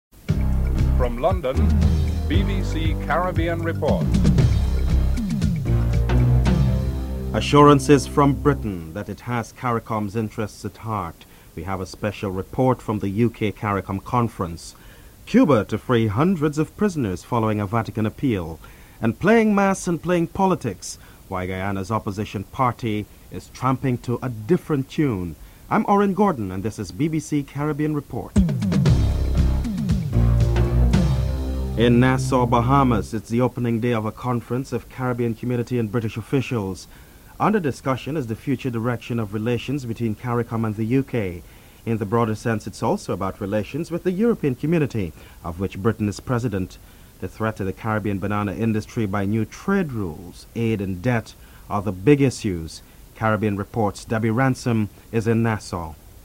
Health Minister Adelina Tuitt states that more money is needed as the island has only one hospital (04:01-06:55)
Colin Croft comments on Brian Lara's innings and the ongoing Carnival celebrations in Trinidad (13:21-14:55)